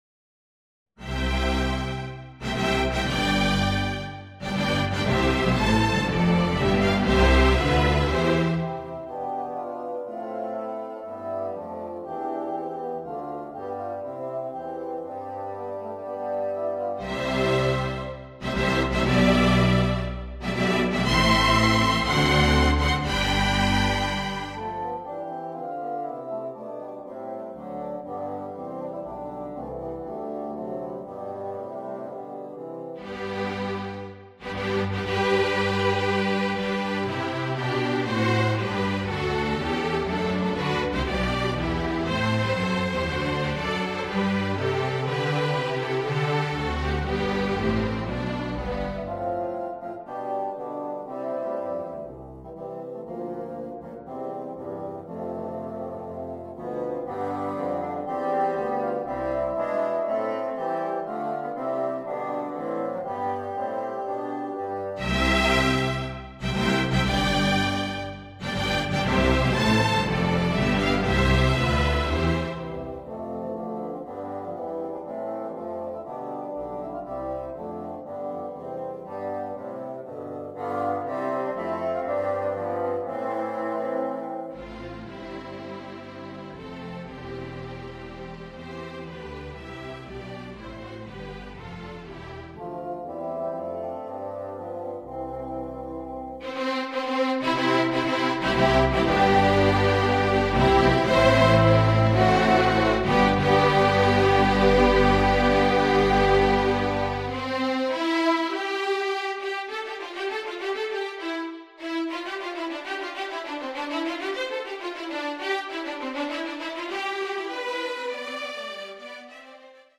4 bassoons, string orchestra
(Audio generated by Sibelius/NotePerformer)
A subtitle reads "(more or less in the style of Handel) "